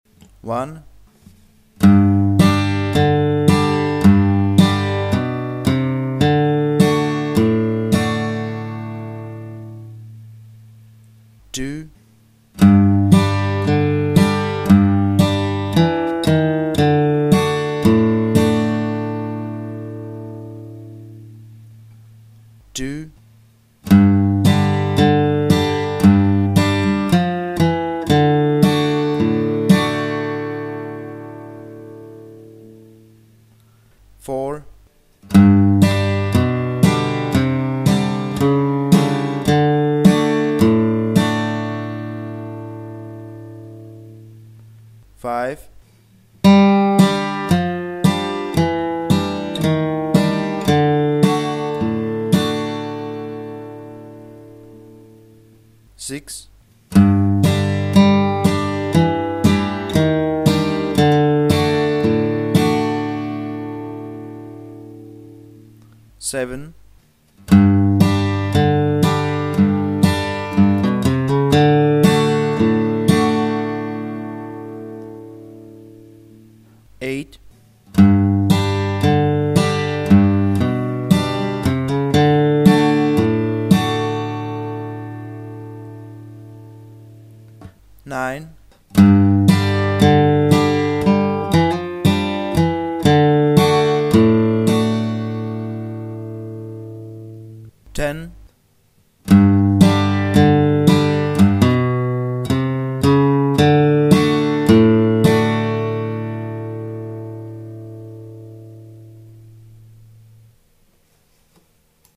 Žánr: Blues.